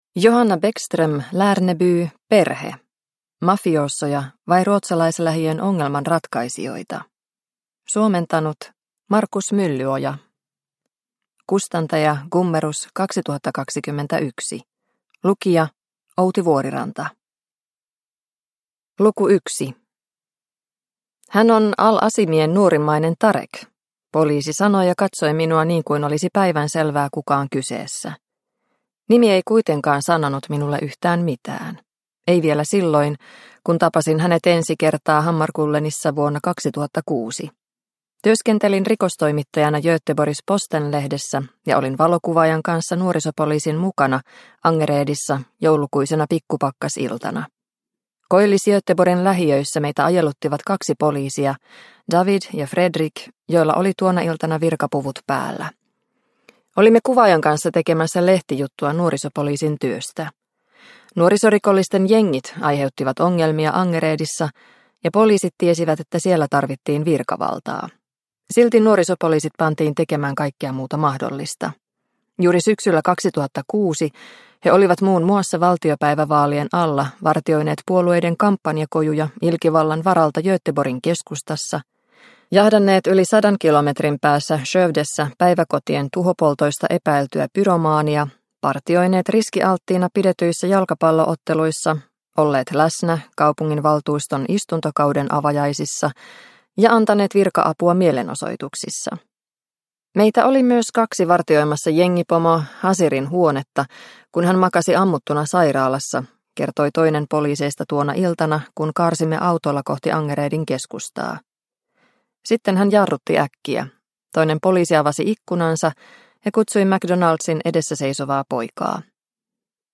Perhe – Ljudbok – Laddas ner